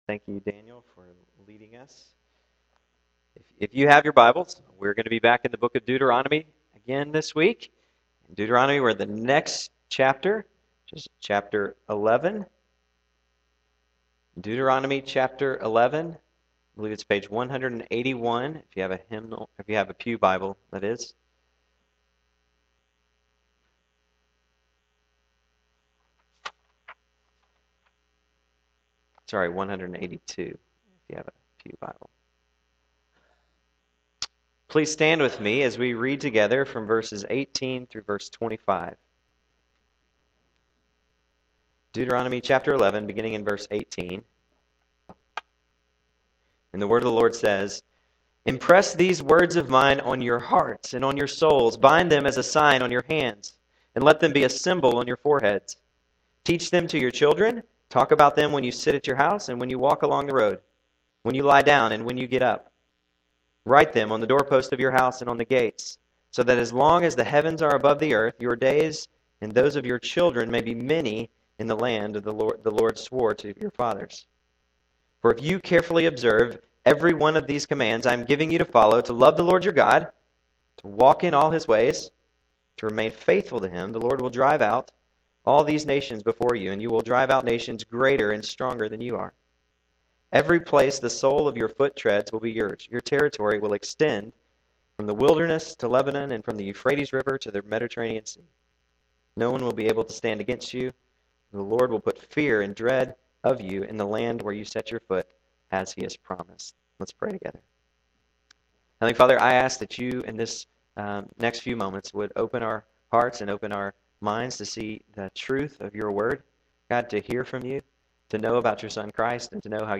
Due to a minor glitch, the sermon is in two parts.